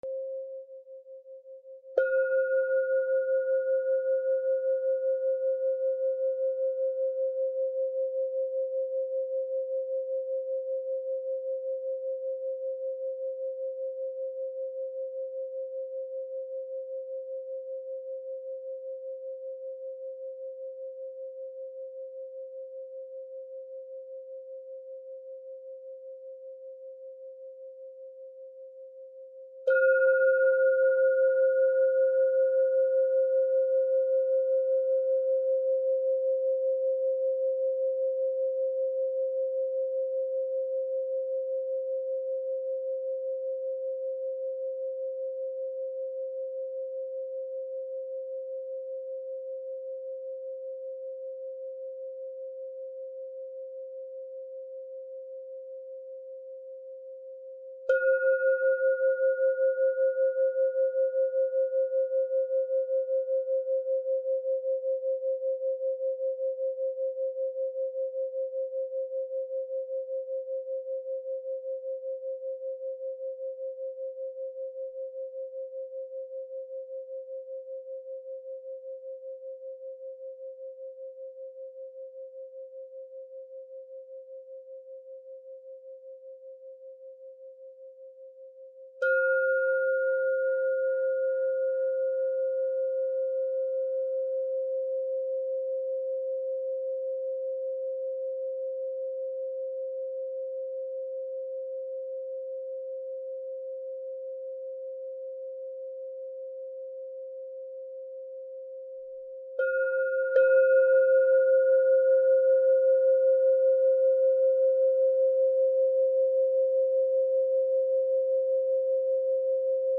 La méditation au son du bol tibétain au mont Hymalaya
CHANTS TIBÉTAINS
528-Hz-meditatiom-bolt-tibetain-antique-son-Himalaya.mp3